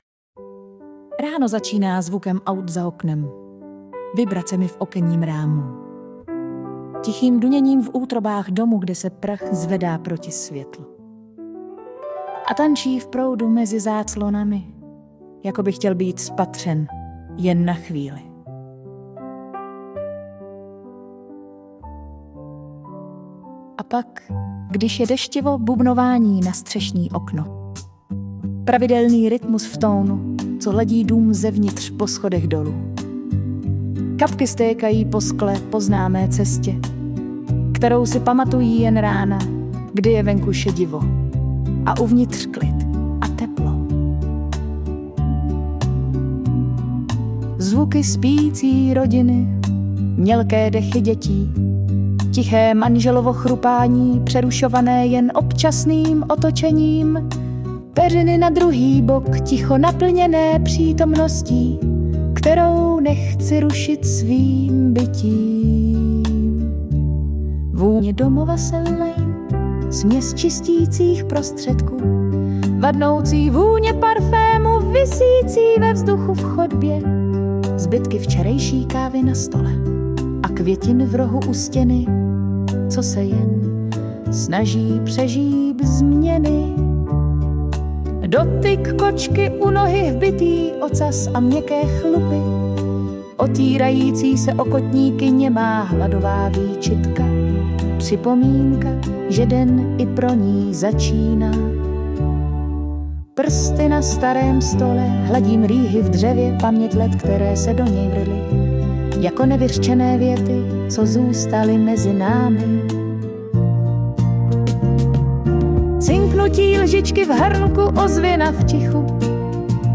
Zhudebnění je u této básně taková spíše hříčka :)